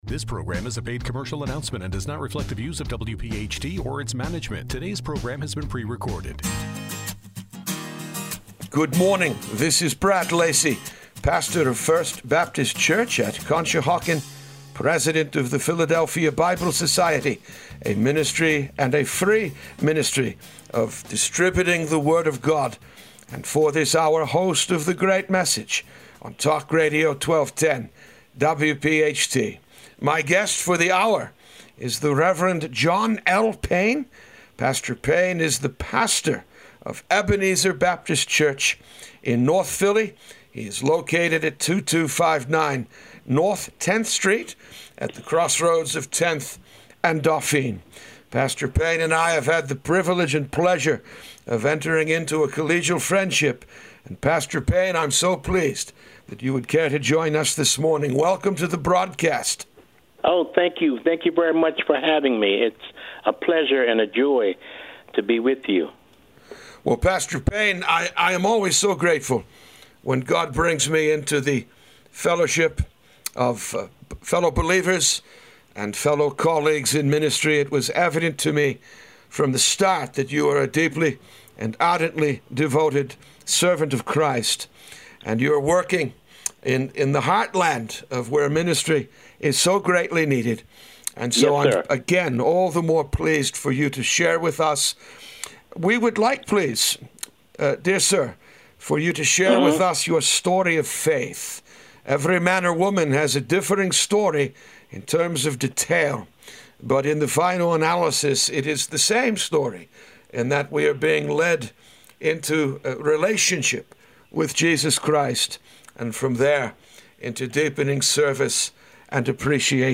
The Great Message A Conversation